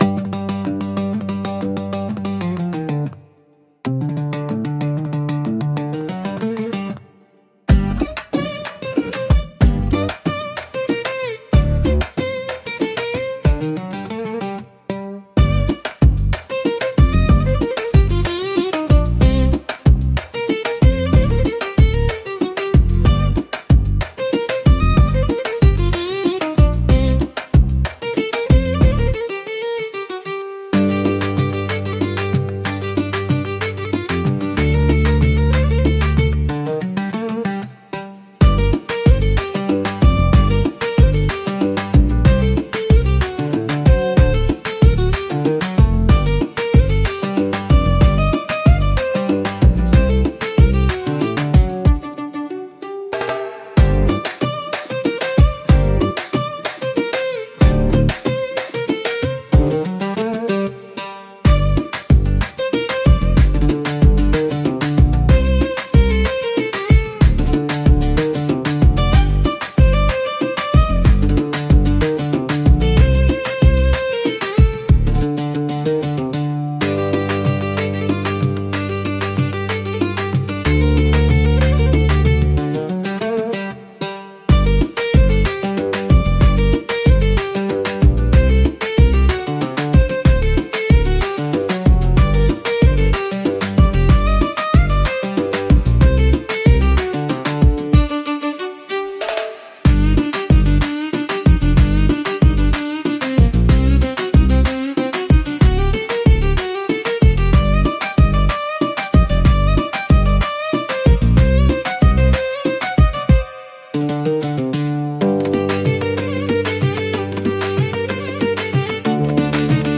独特のメロディとリズムで聴く人の感覚を刺激します。